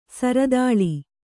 ♪ saradāḷi